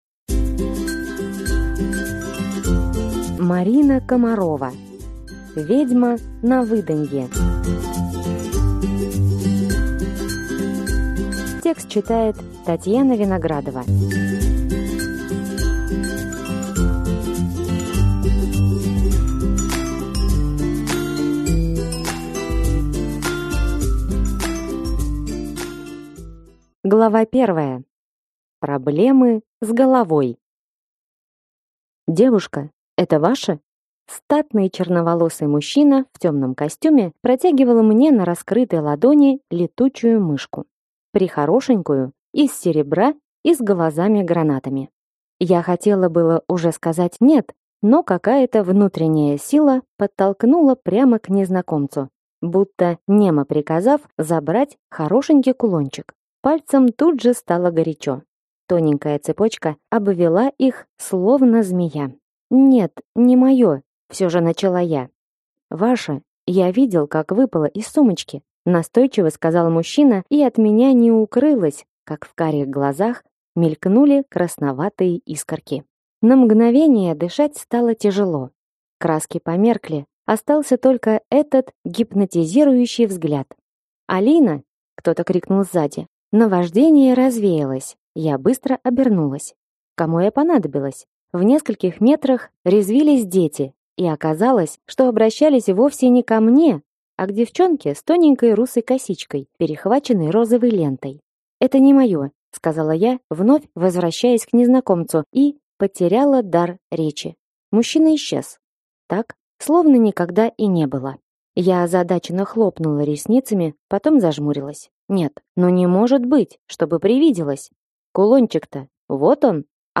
Аудиокнига Ведьма на выданье | Библиотека аудиокниг